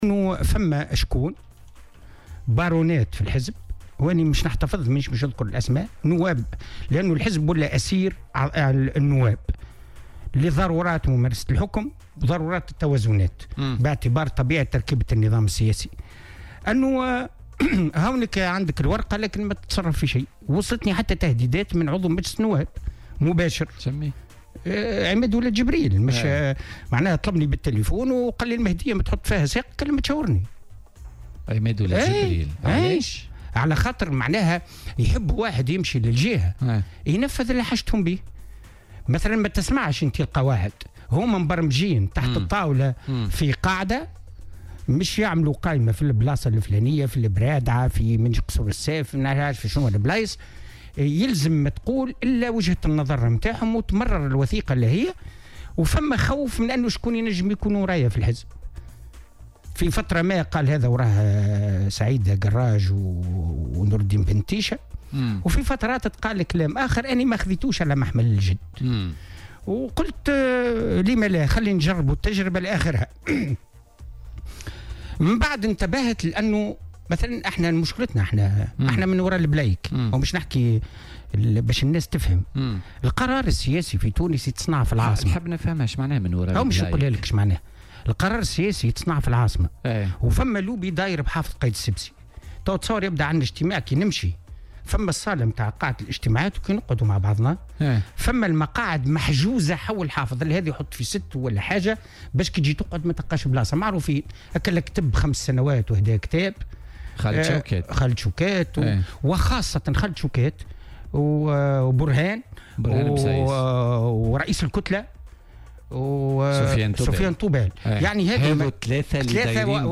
تحدث جمال بوغلاب، ضيف برنامج "بوليتيكا" اليوم الأربعاء عن أسباب استقالته من حزب نداء تونس.